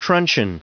Prononciation du mot truncheon en anglais (fichier audio)
truncheon.wav